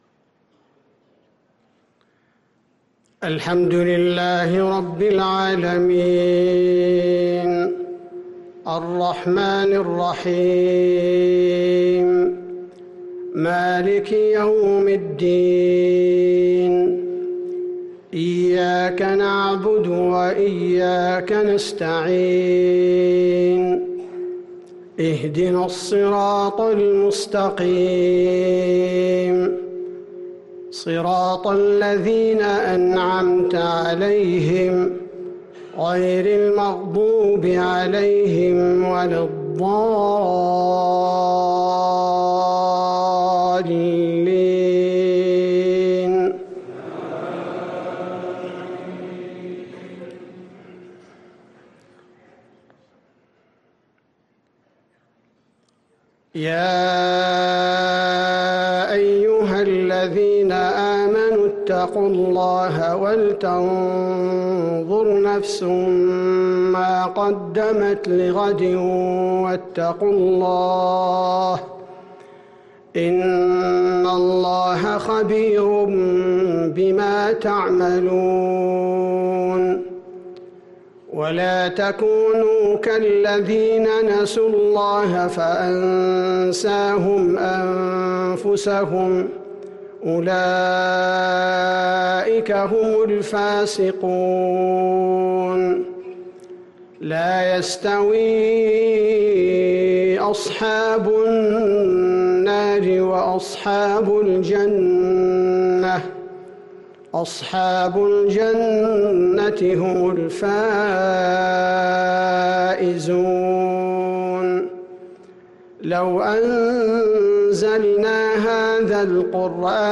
عشاء الأحد 6-3-1444هـ من سورتي الحشر و الجمعة | Ishaa prayer from surat Al-Hashr & Al-Jumu'a > 1444 🕌 > الفروض - تلاوات الحرمين